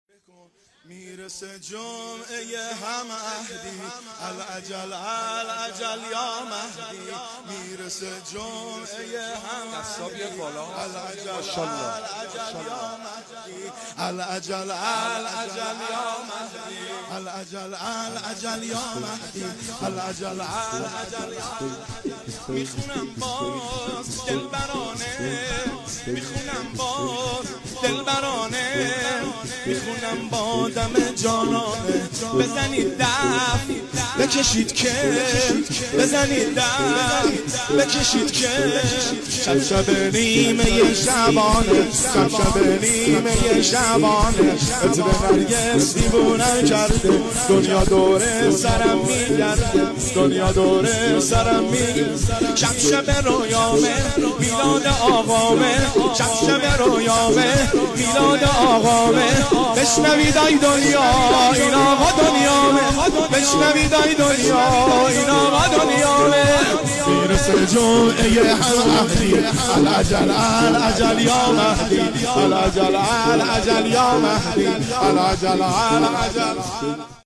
سرود میلاد حضرت مهدی (عج) 1402
هیئت درب شازده شیراز